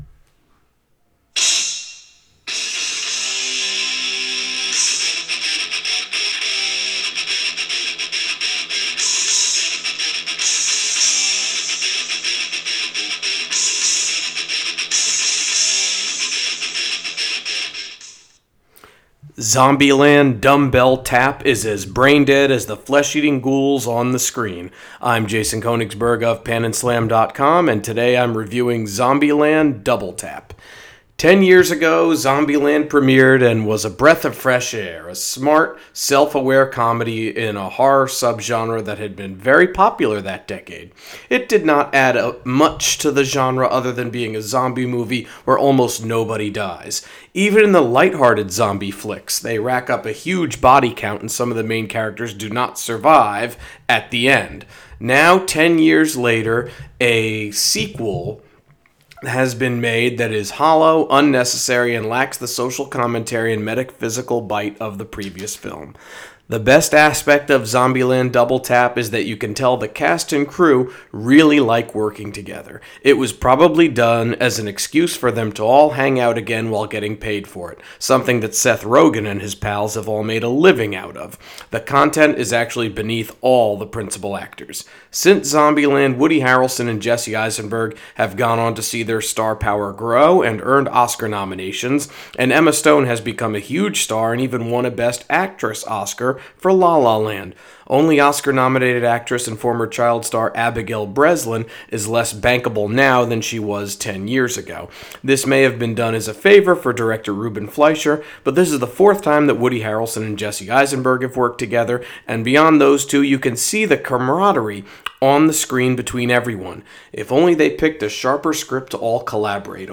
Movie Review: Zombieland: Double Tap